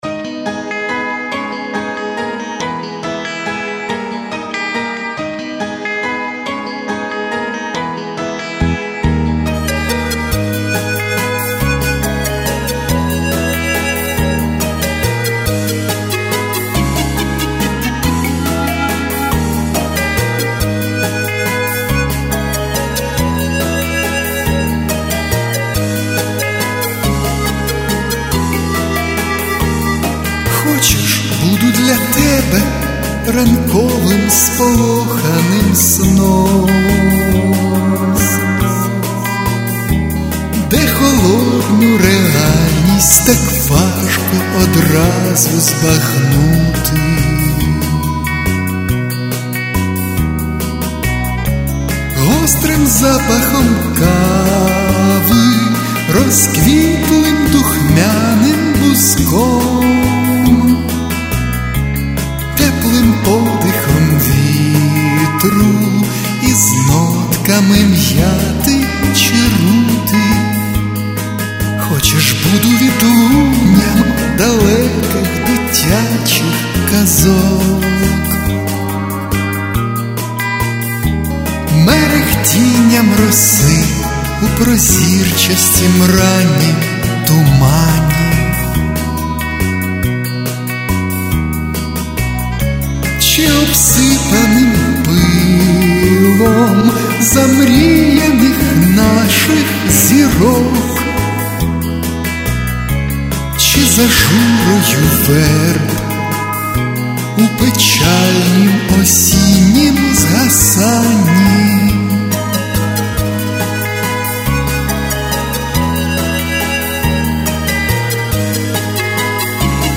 Рубрика: Поезія, Авторська пісня
І Ви так магічно співаєте!
Ніжно і лірично!Вірші від цього тільки виграли!